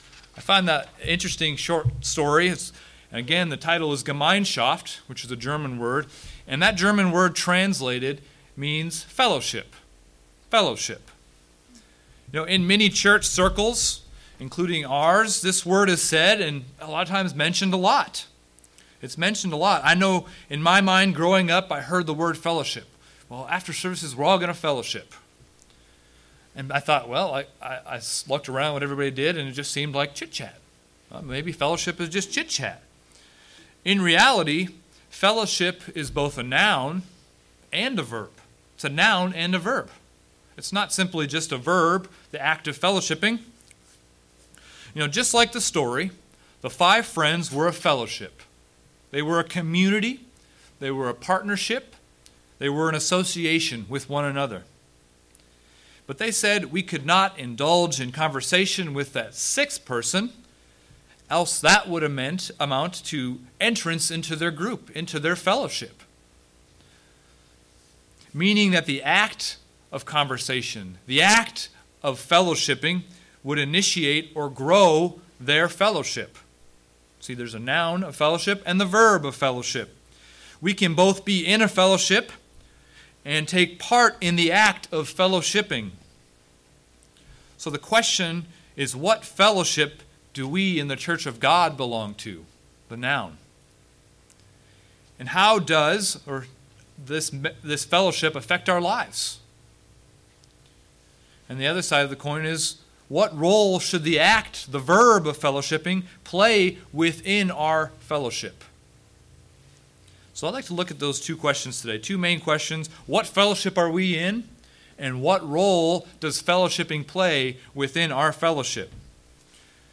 This sermon looks to define both aspects of fellowship and the impact this has on our daily lives.
Given in Springfield, MO